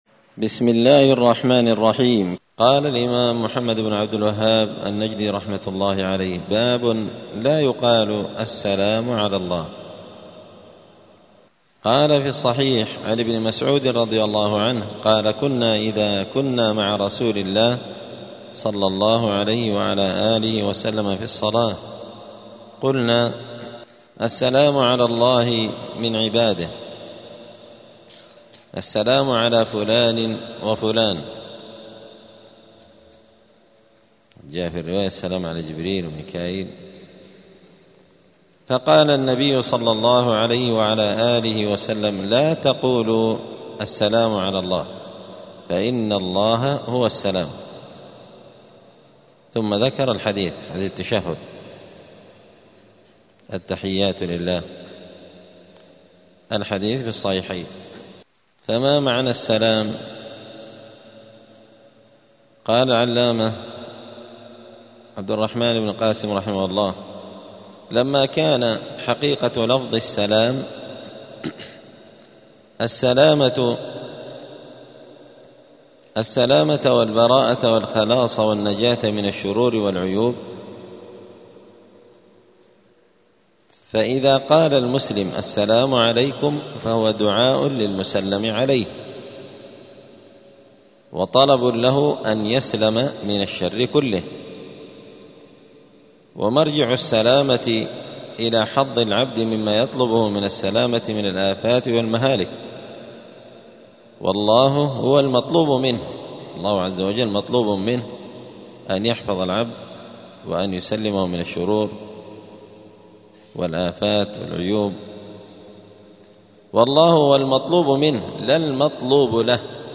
دار الحديث السلفية بمسجد الفرقان قشن المهرة اليمن
*الدرس الثاني والثلاثون بعد المائة (132) {باب لا يقال السلام على الله}*